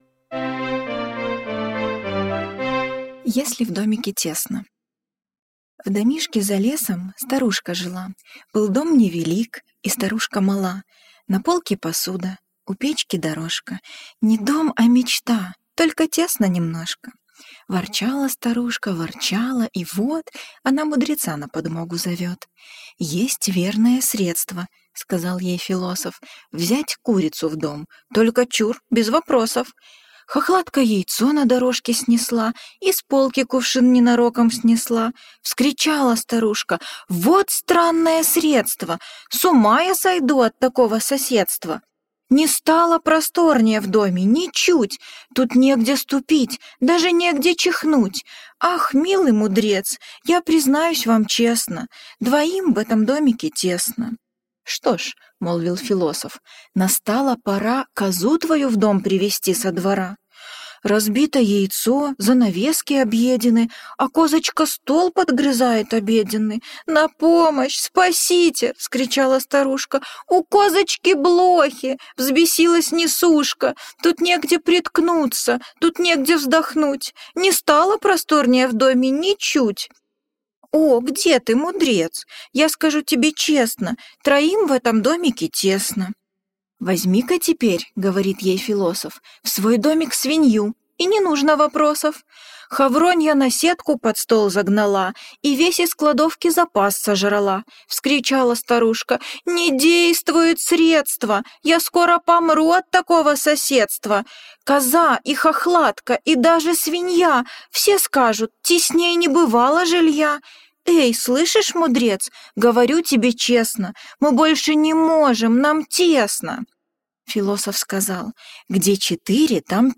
Если в домике тесно - аудиосказка Джулии Дональдсон - слушать онлайн